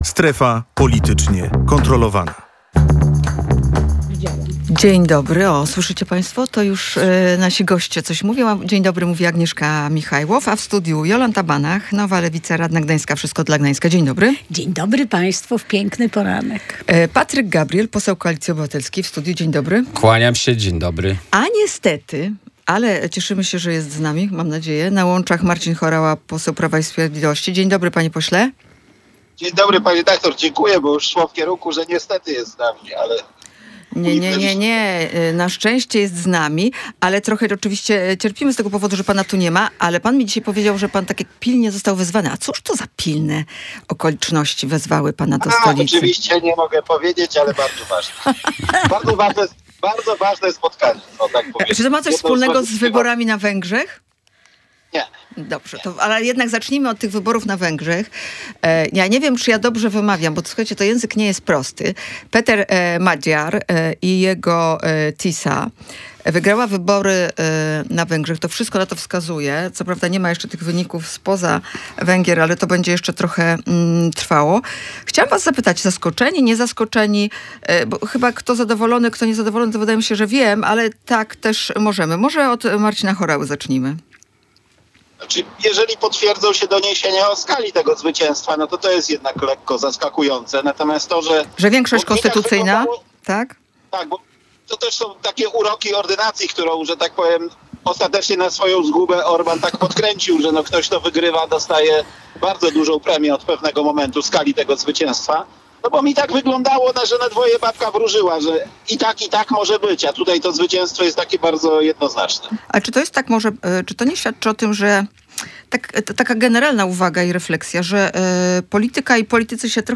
W dyskusji wzięli udział Patryk Gabriel z Koalicji Obywatelskiej, Marcin Horała z Prawa i Sprawiedliwości oraz Jolanta Banach, radna gdańska z Nowej Lewicy, należąca do formacji Wszystko dla Gdańska.